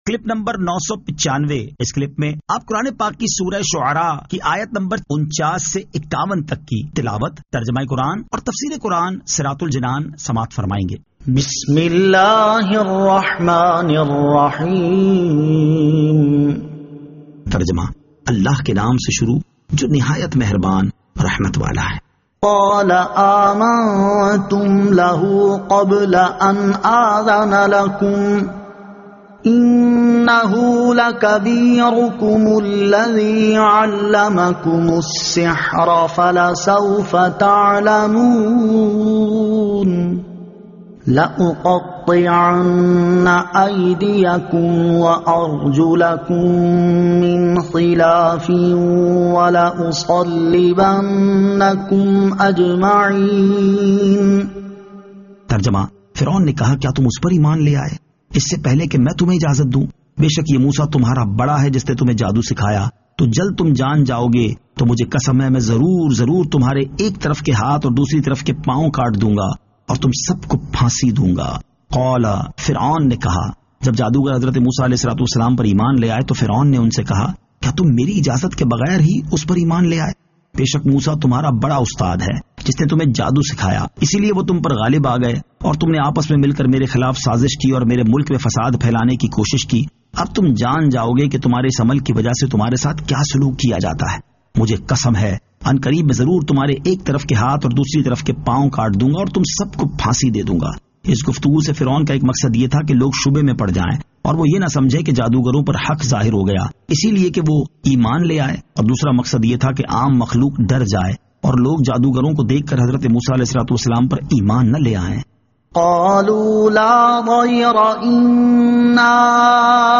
Surah Ash-Shu'ara 49 To 51 Tilawat , Tarjama , Tafseer